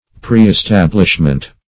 Preestablishment \Pre`["e]s*tab"lish*ment\, n. Settlement beforehand.